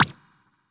flip1.ogg